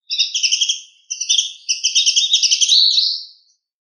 「キュルキュルキュル」と愛らしく響く、春を告げるスズメ目メジロ科に属する日本の代表的な小鳥。
「キュルキュルキュル」メジロの鳴き声 着信音